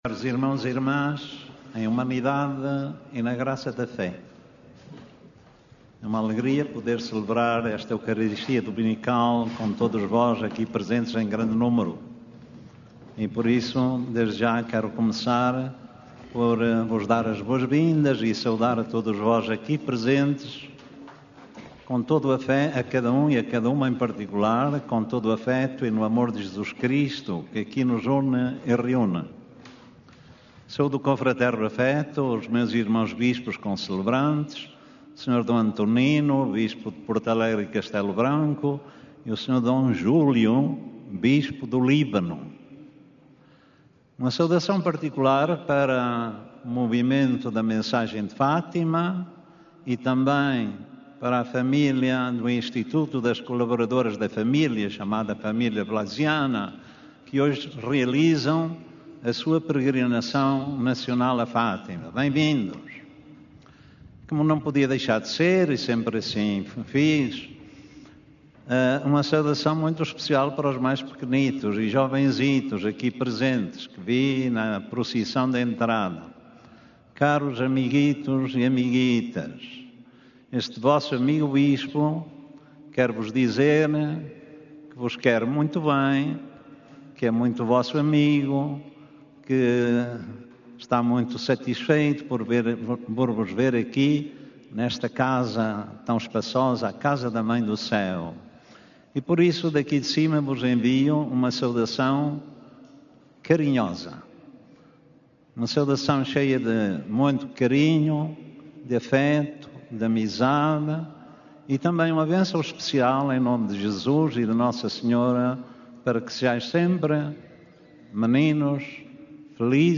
Na homilia que proferiu, este domingo, no Recinto de Oração, o cardeal D. António Marto destacou a hospitalidade e o acolhimento como virtudes humanas e cristãs.
Na missa deste domingo, celebrada no Recinto de Oração, o cardeal D. António Marto, bispo emérito de Leiria-Fátima, exortou os peregrinos a serem promotores da cultura do acolhimento, nas suas famílias, nas comunidades e nas sociedades.